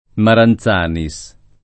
[ maran Z# ni S ]